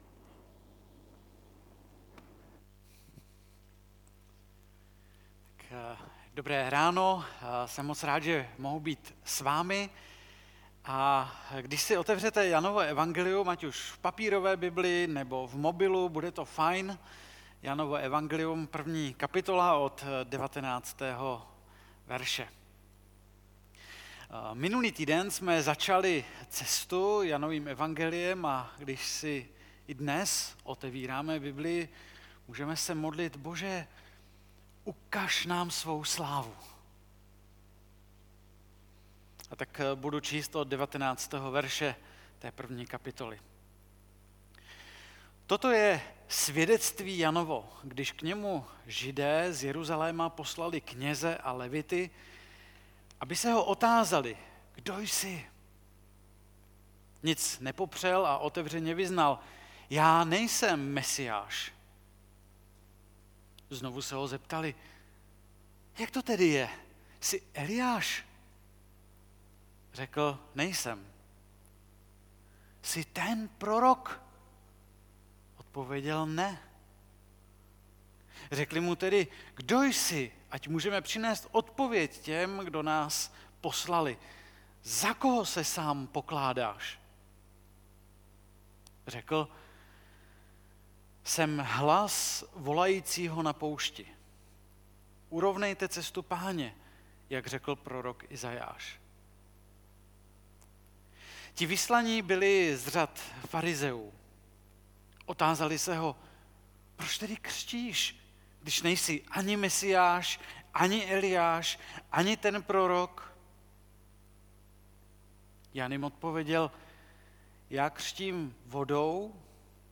2. kázání ze série Záblesky slávy (Jan 1,19-34]
Kategorie: Nedělní bohoslužby